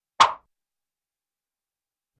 Звуки взмахов